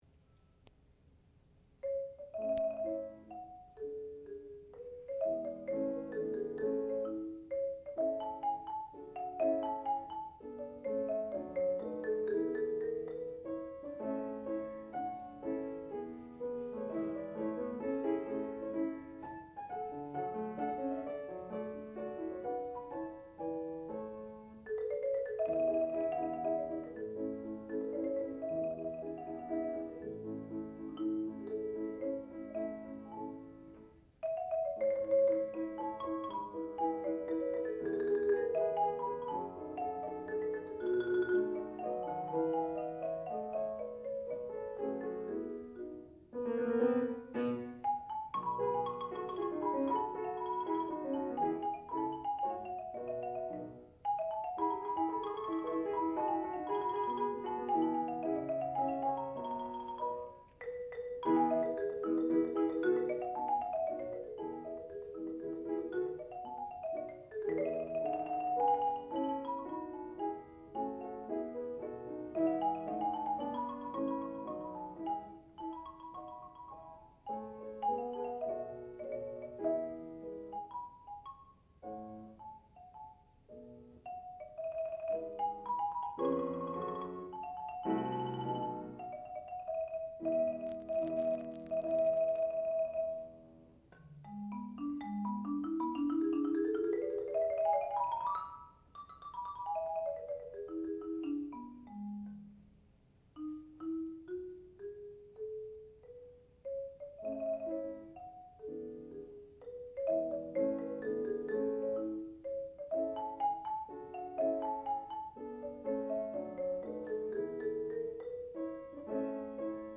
sound recording-musical
classical music
piano
marimba